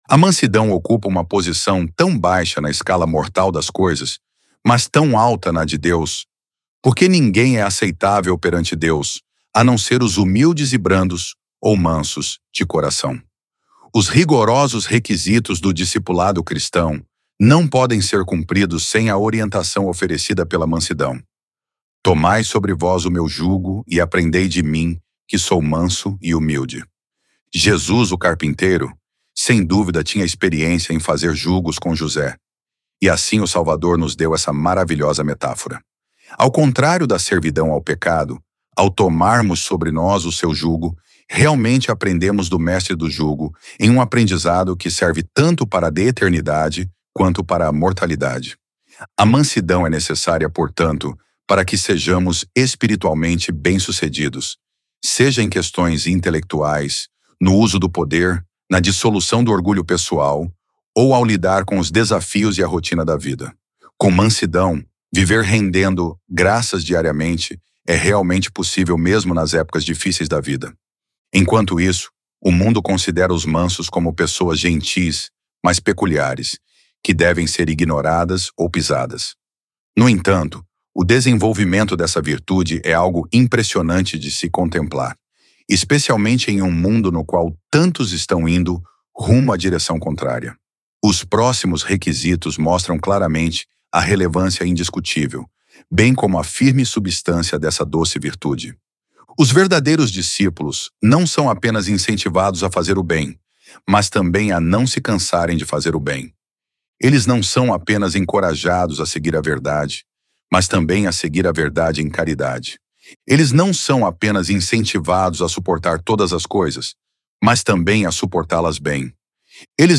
Membro do Quórum dos Doze Apóstolos de A Igreja de Jesus Cristo dos Santos dos Últimos Dias